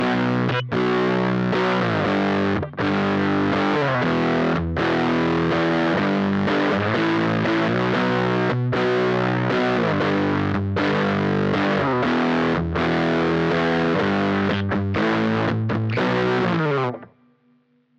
Das Komet KODA besticht durch eine sehr sahnige, hochkomprimierte typische US-Amerikanische Verzerrung.
Hier ist gut die sehr komprimierte Verzerrung des Komet Koda gut zu hören. Zunächst ohne Output-Stage Funktion, danach ohne.